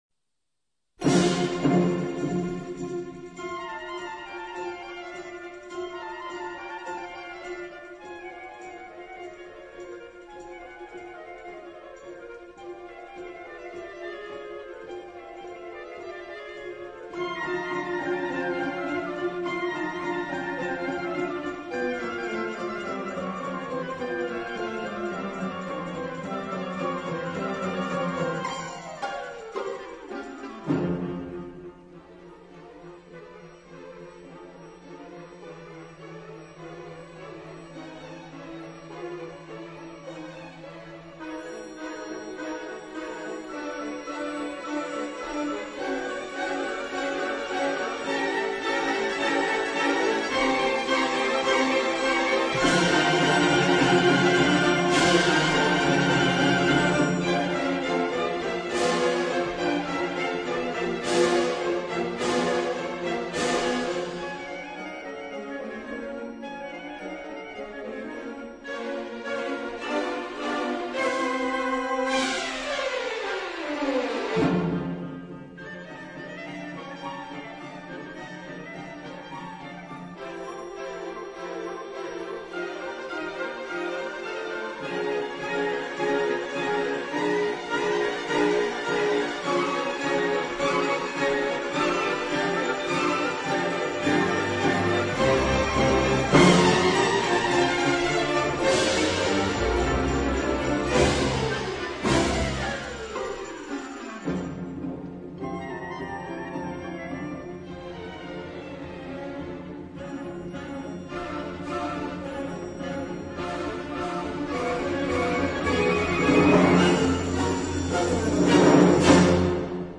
音樂類型：古典音樂
錄音優秀，有強大動態與浮凸音像，強奏與高潮處更能感受其無與倫比。